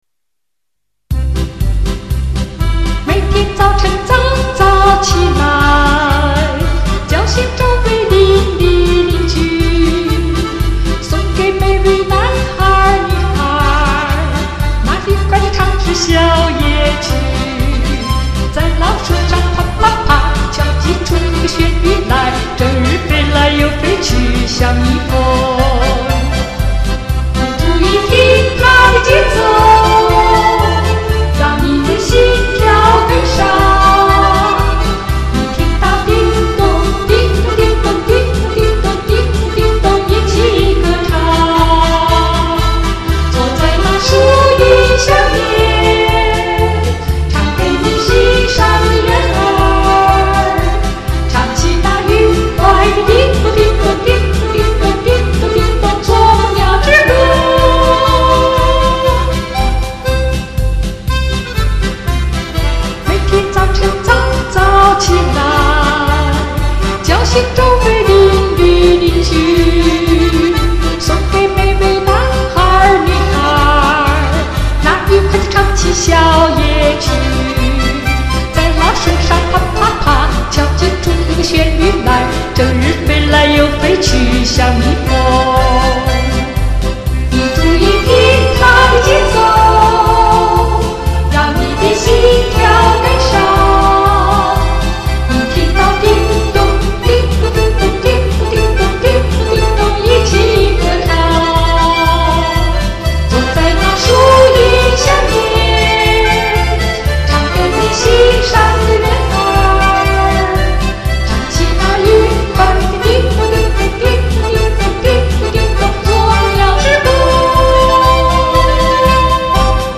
奥地利民歌 女声独唱